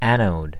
Ääntäminen
US : IPA : /ˈæn.oʊd/ UK : IPA : /ˈæn.əʊd/